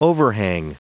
Prononciation du mot overhang en anglais (fichier audio)
Prononciation du mot : overhang